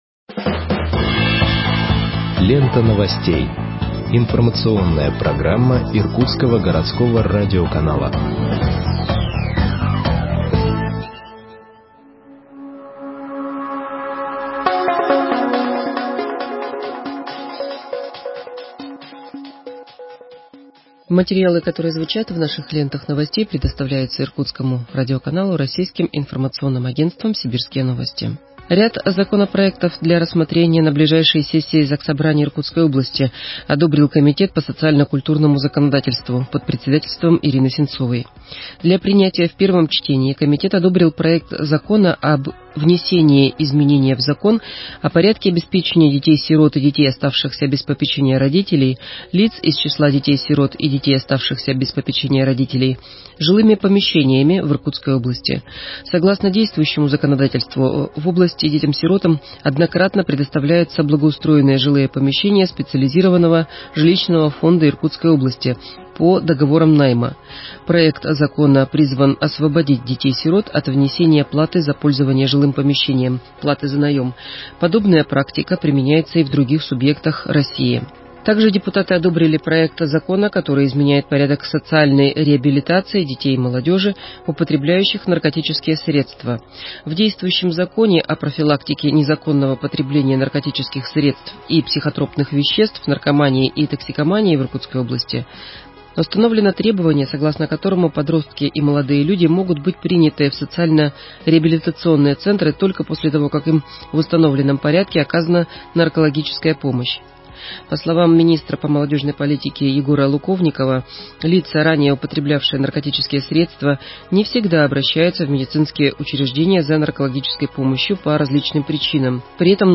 Выпуск новостей в подкастах газеты Иркутск от 25.01.2021 № 2